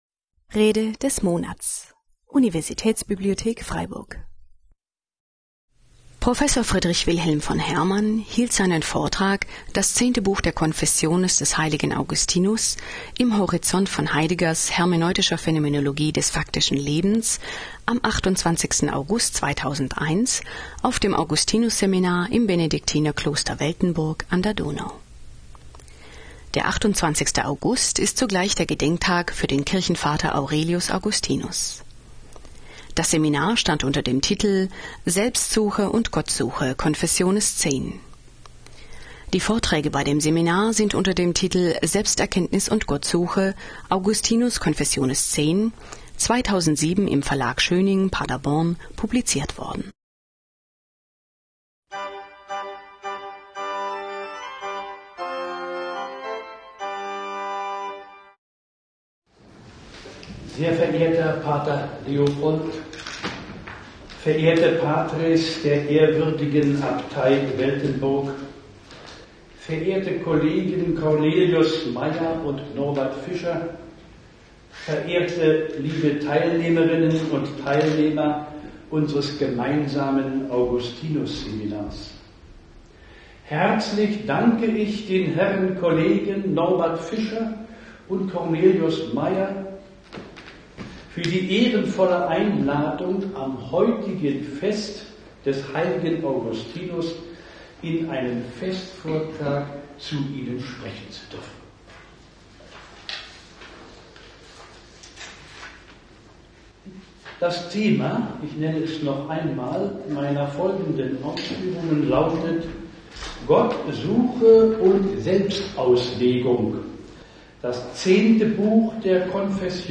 Vortrag
August 2001 auf dem Augustinus-Seminar 2001 im Benediktiner-Kloster Weltenburg an der Donau.